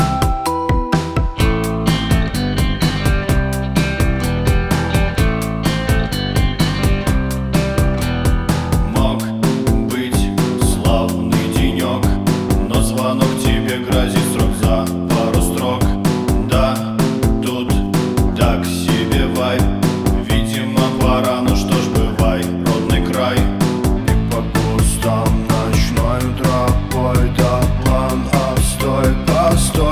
Жанр: Рок / Русские